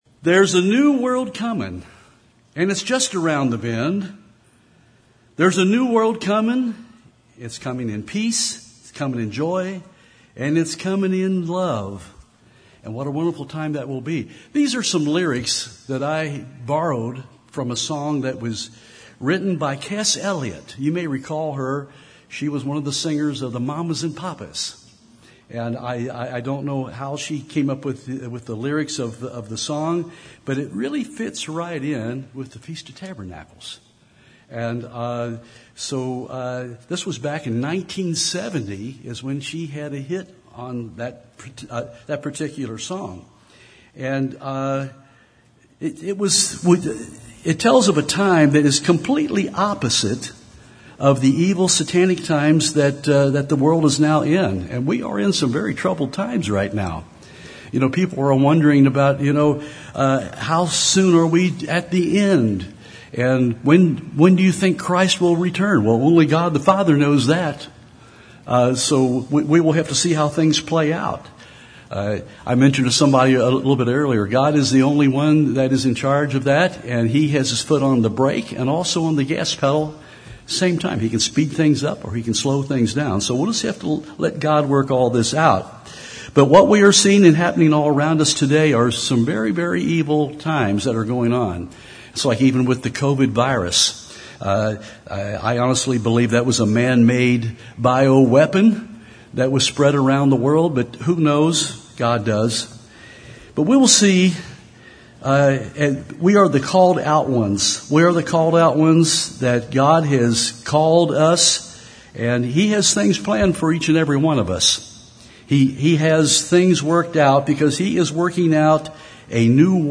This sermon was given at the Cincinnati, Ohio 2021 Feast site.